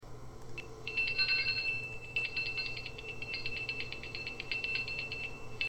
bell